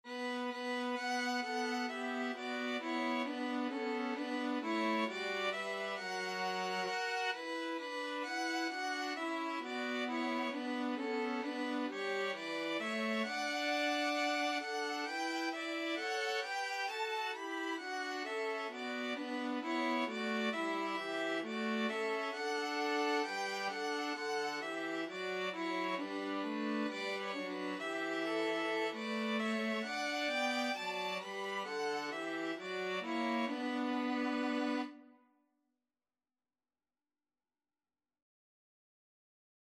Violin 1Violin 2Viola
Traditional Music of unknown author.
The melody is in the minor mode.
4/4 (View more 4/4 Music)